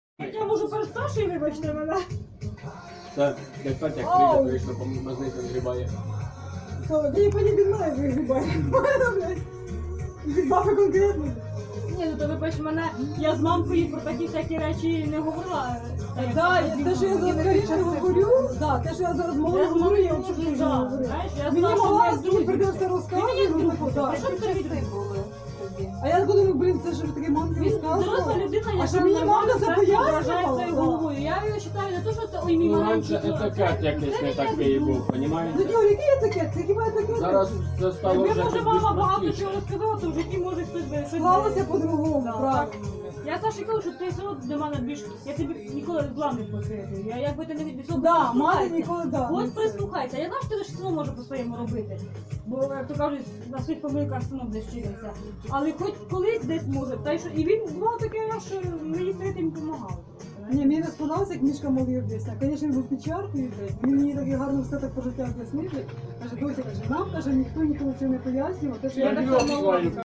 7. Вечеринка в квартире, разговоры людей, музыка на фоне